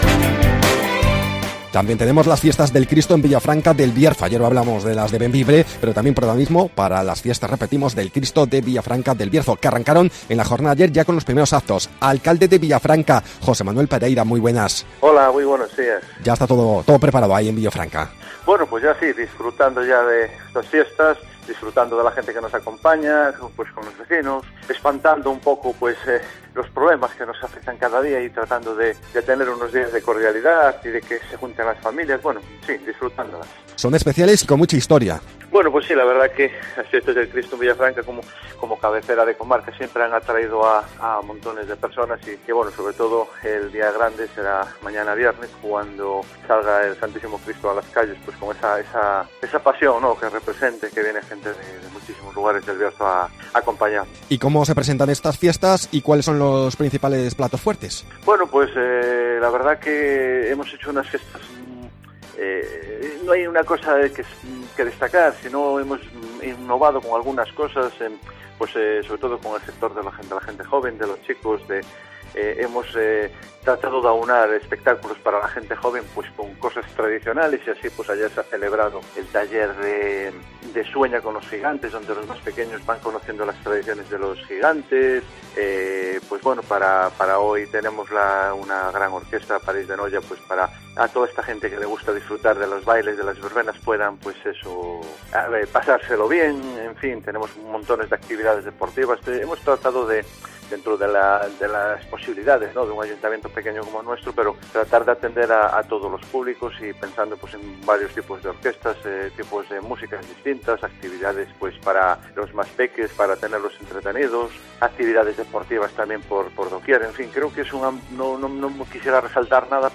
Escucha aquí la entrevista con el alcalde de Villafranca del Bierzo, José Manuel Pereira, donde nos da detalles de las fiestas del Cristo 2018